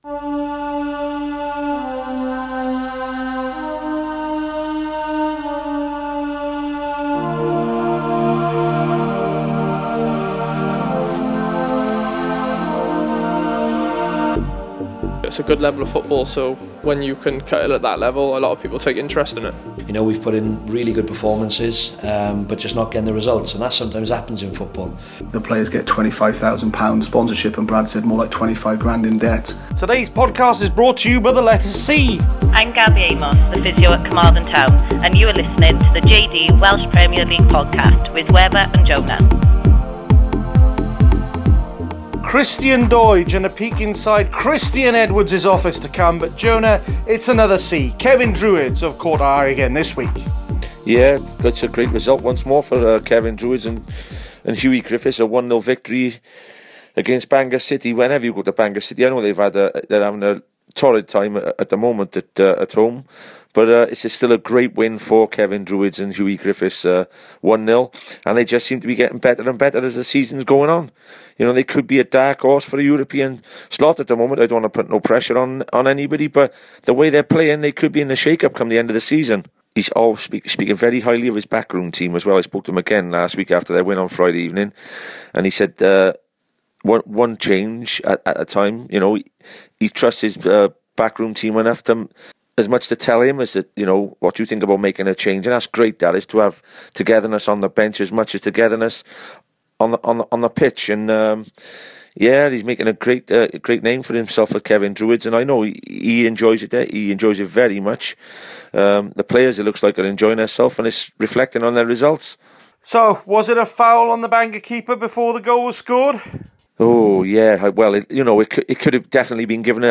Plus the usual manager and player interviews.